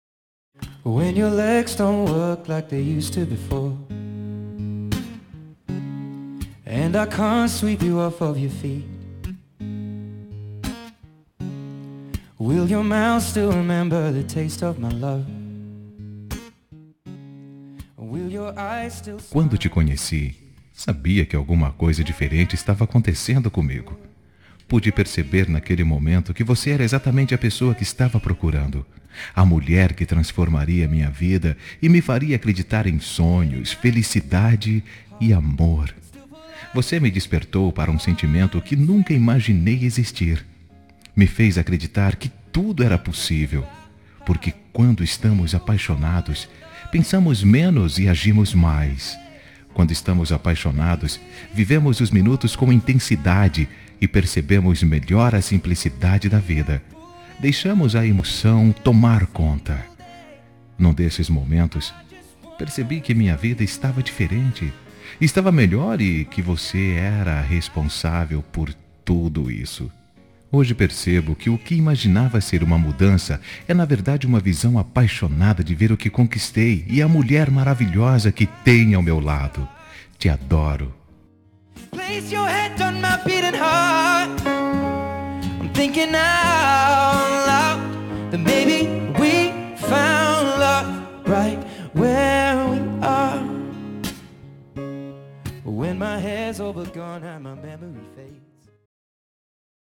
Telemensagem Início de Namoro – Voz Masculina – Cód: 752